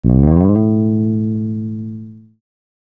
A SLIDE UP.wav